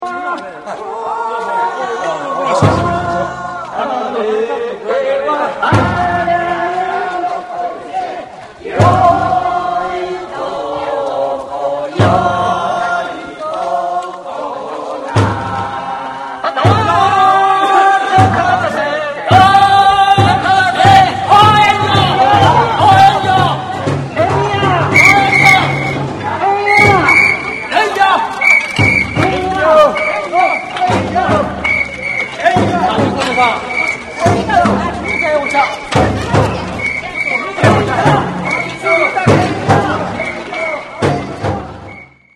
Internet Exprlorerで開くと、自動的に１回だけ、乙見神社からの神楽笛太鼓が遠くに聞かれます。
「ピィ、ピィ＝エンヤ、ドンドン＝トットッ、ピィ、ピィ＝エンヤ、ドンドン＝トットッ、ピィ、ピィ＝エンヤ、ドンドン＝トットッ・・・」このようは雰囲気の音収録は初めてで、カメラ2台とMDを操作する